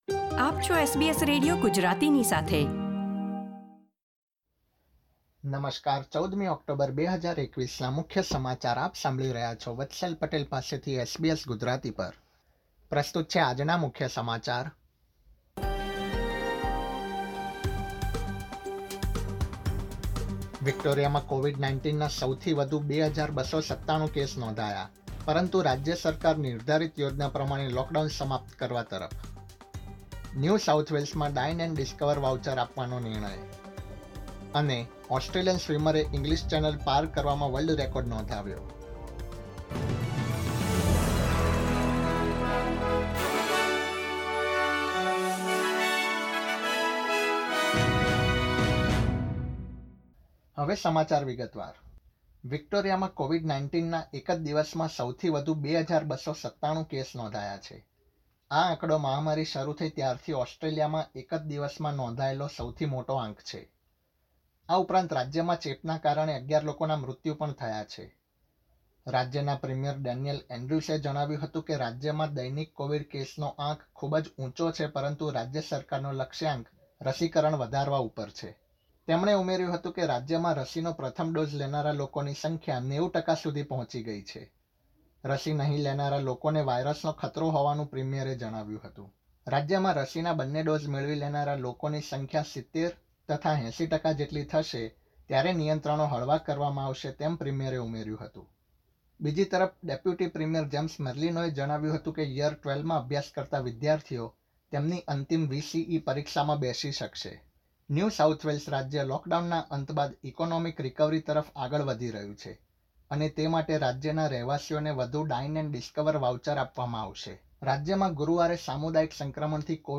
SBS Gujarati News Bulletin 14 October 2021